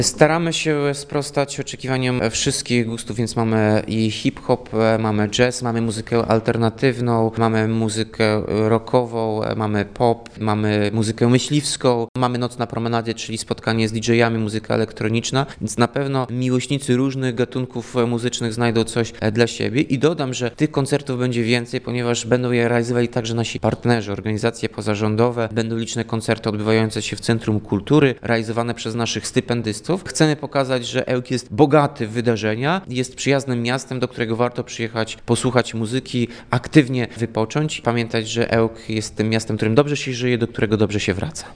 – Ten rok będzie bogaty w przeróżne wydarzenia muzyczne- przyznaje Tomasz Andrukiewicz, prezydent Ełku.